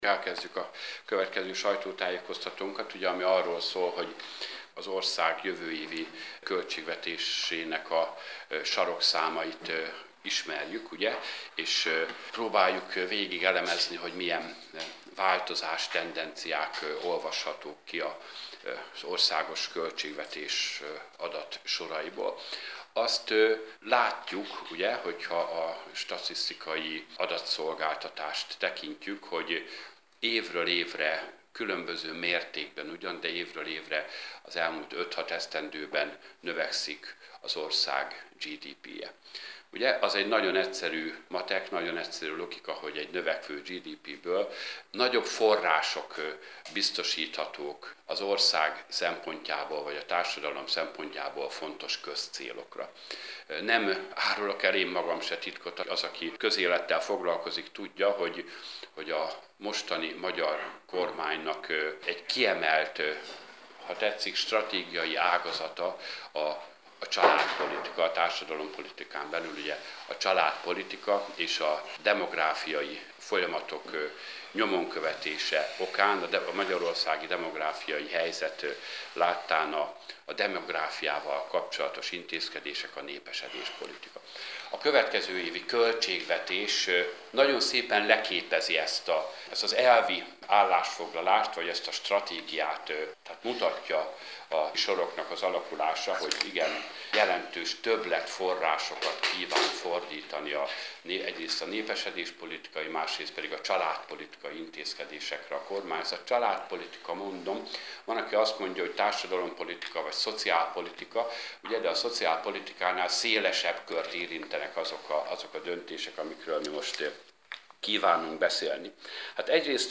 Sajtótájékoztatót tartott